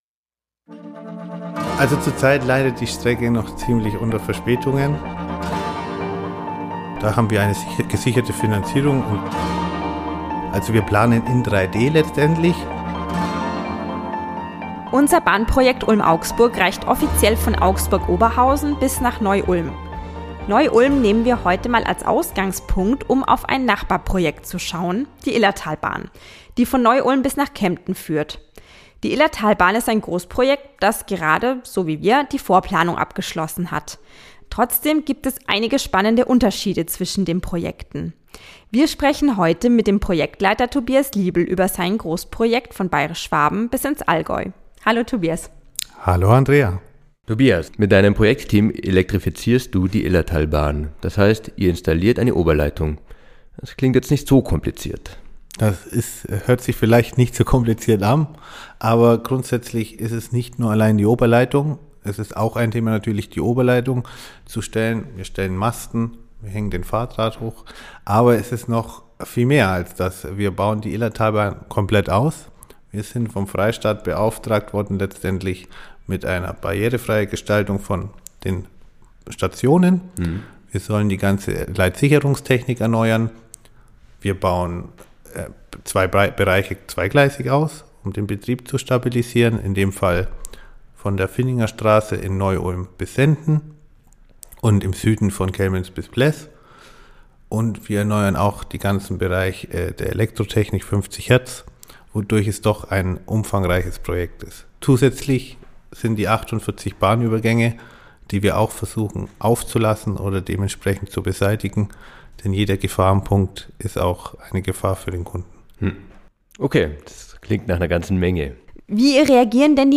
Ein Gespräch über Komplexität, Kundenkomfort und klare Kommunikation.